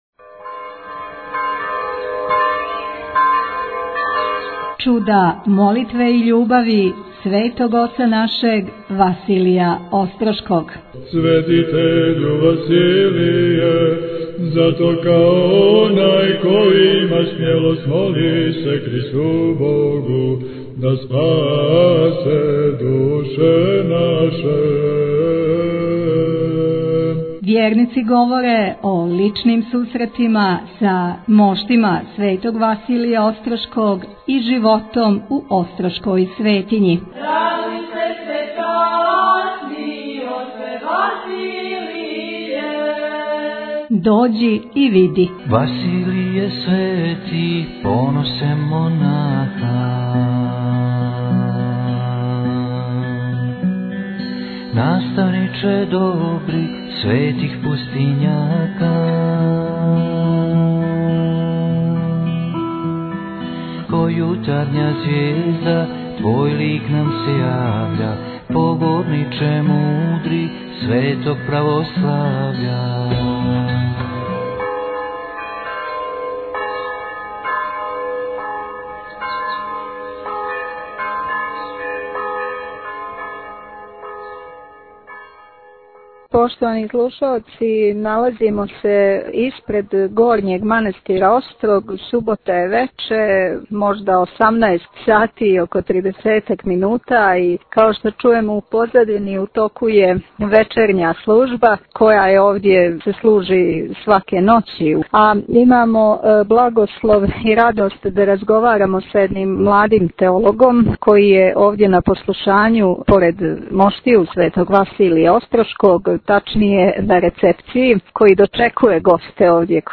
Овог љета имали смо благослов да у манастиру Острог, поред моштију Светог Василија Острошког Чудотворца, снимимо више интересантних разговора са нашим свештеницима и поклоницима Острошке Светиње.